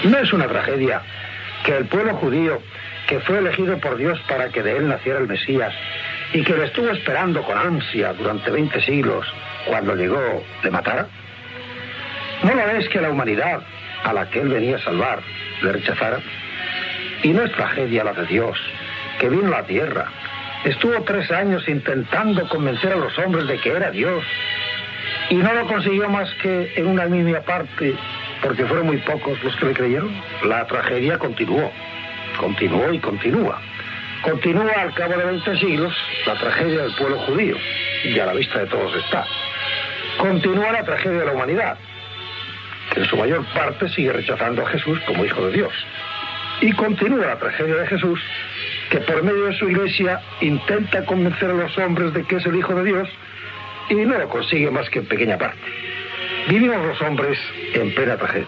Religió
Fragment extret del programa "La radio con botas", emès l'any 1991 per Radio 5